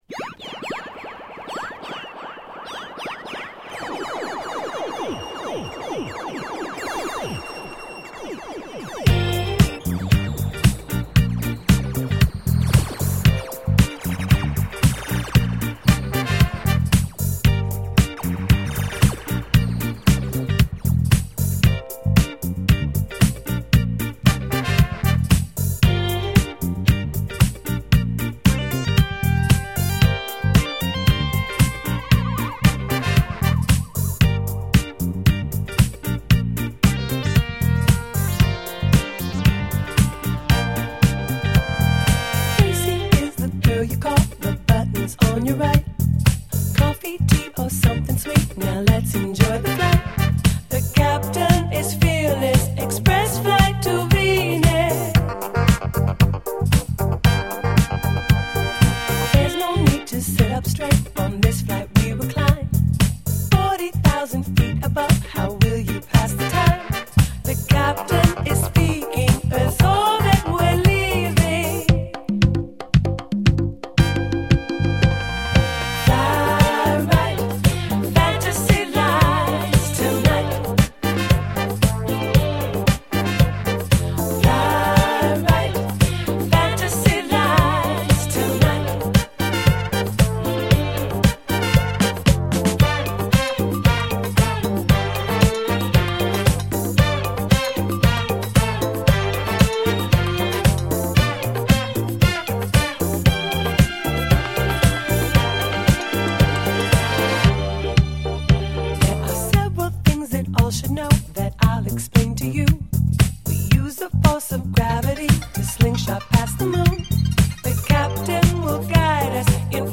An early disco house mix